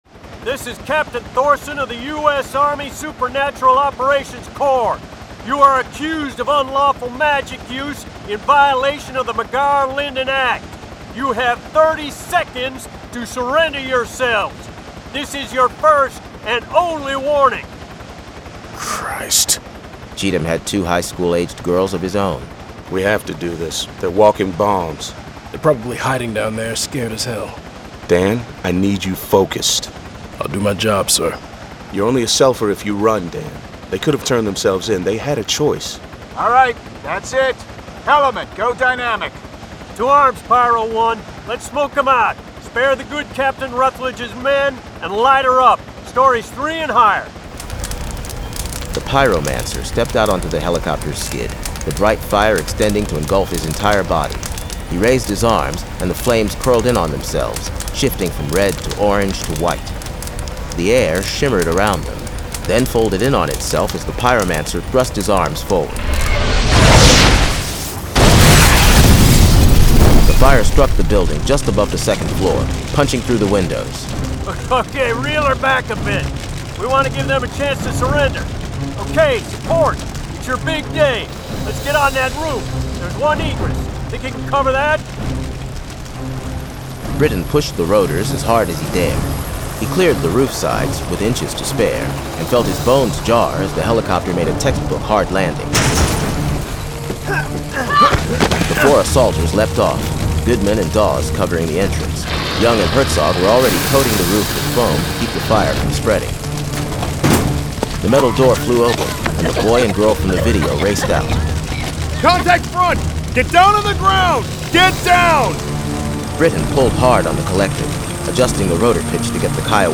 Full Cast. Cinematic Music. Sound Effects.
Genre: Urban Fantasy